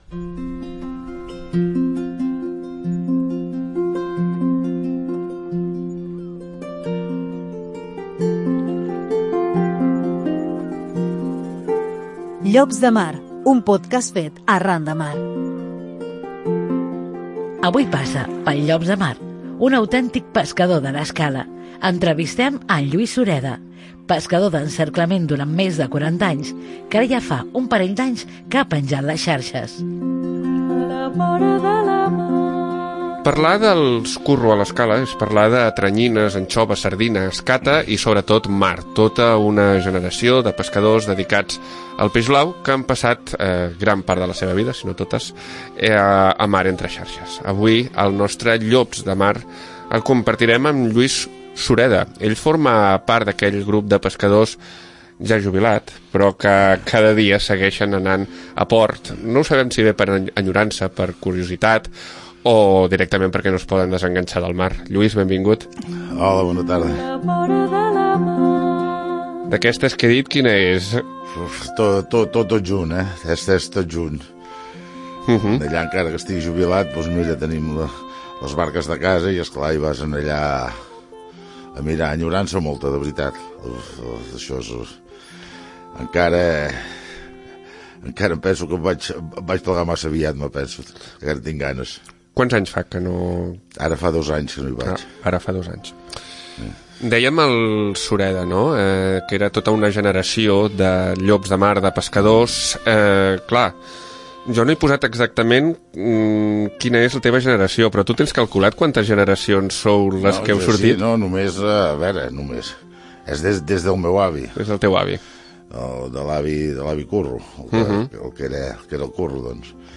Avui passa pel Llops de Mar un autèntic pescador de l’Escala.